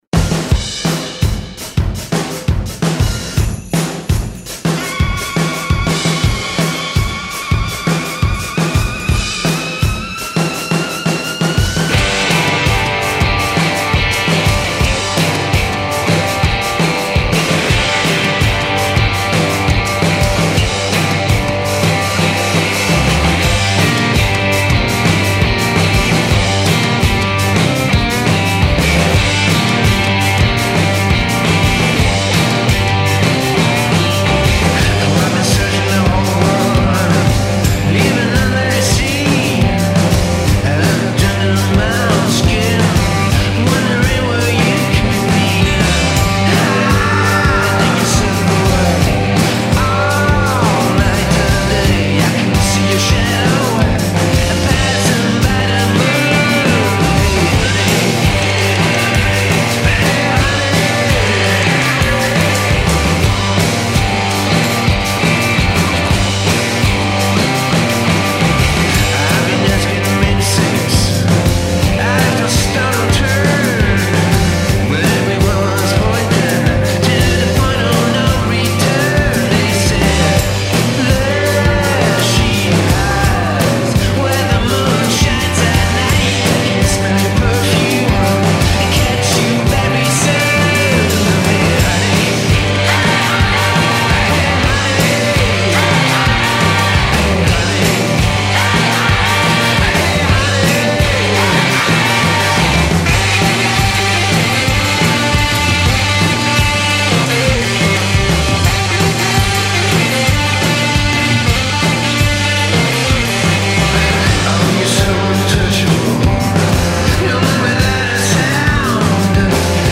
Definitely not bowing out quietly, that's for sure.